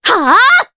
Ophelia-Vox_Attack4_jp.wav